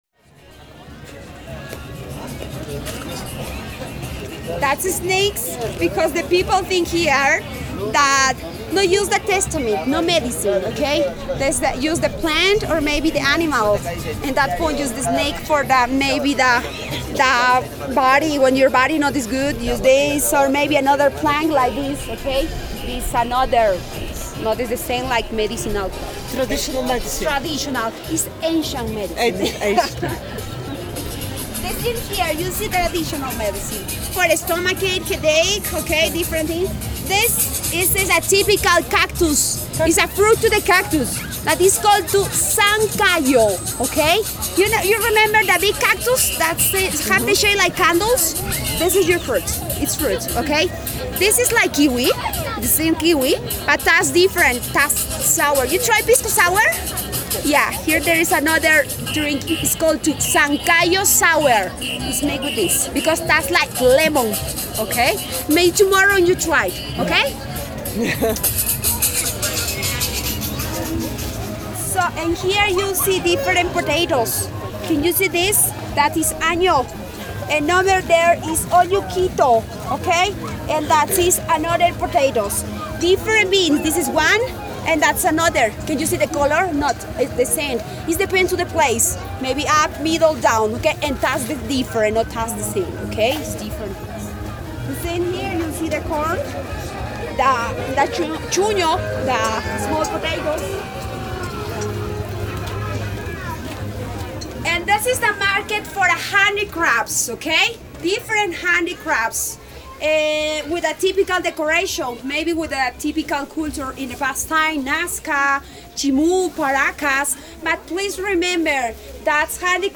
Chivay-Public-market.mp3